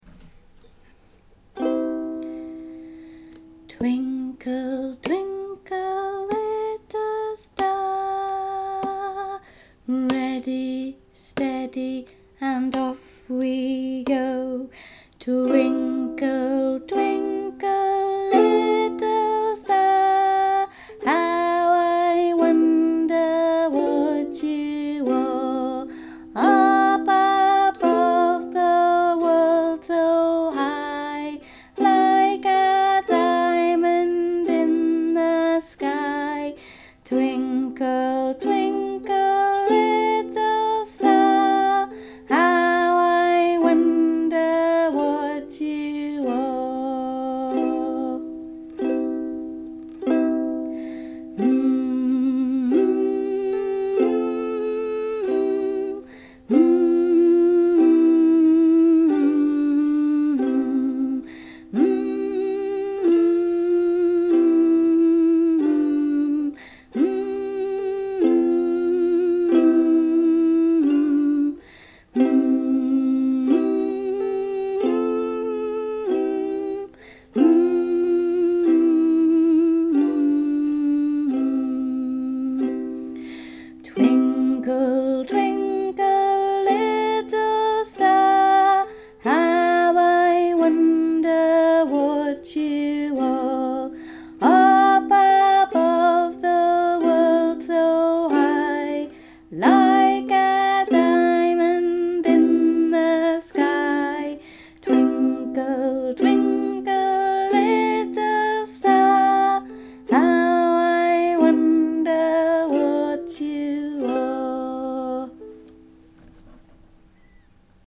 Twinkle-with-ukulele.mp3